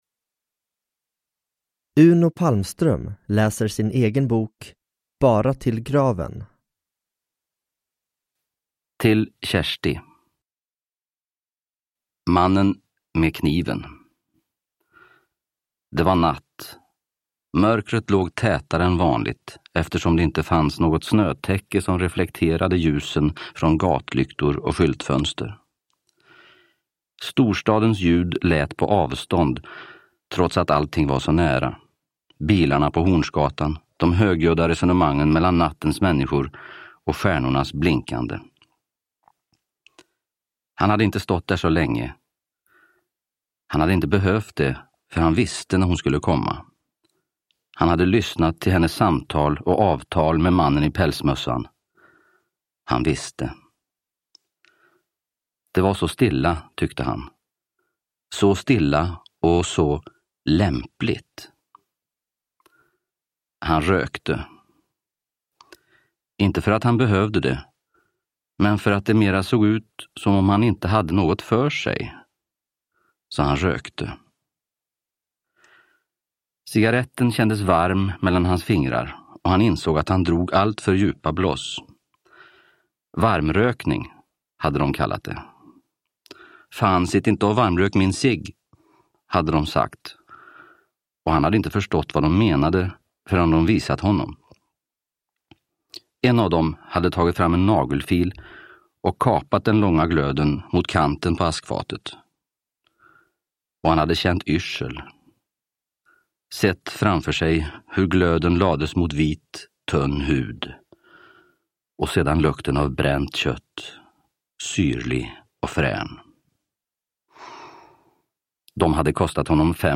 Bara till graven – Ljudbok – Laddas ner